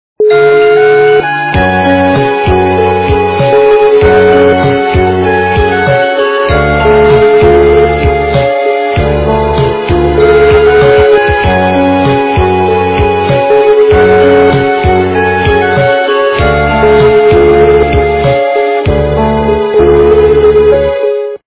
качество понижено и присутствуют гудки